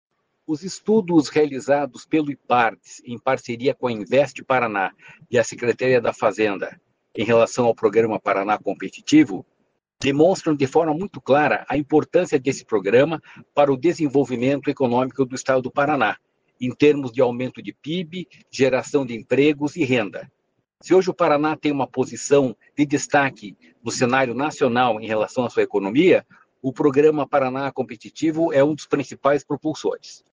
Sonora do diretor-presidente do Ipardes, Jorge Callado, sobre o retorno do programa Paraná Competitivo ao PIB do Estado